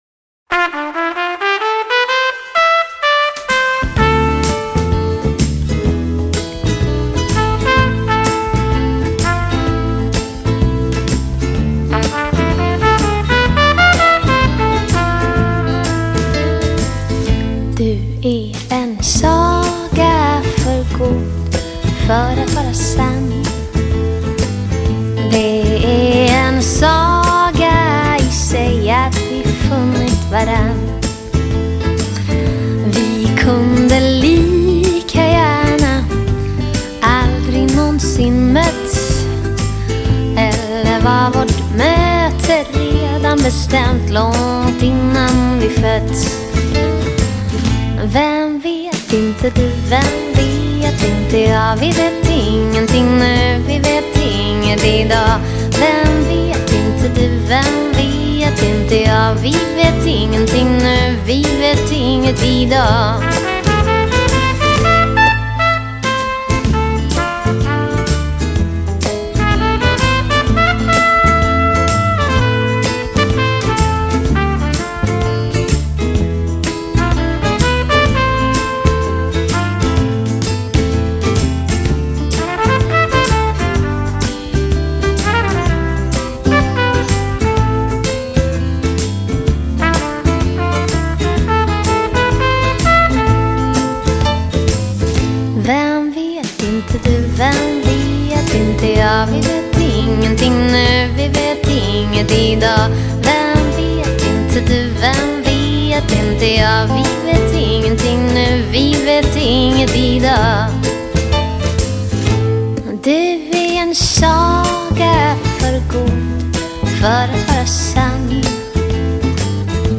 她童稚而沙哑声音习惯在夜晚覆盖整个欧陆。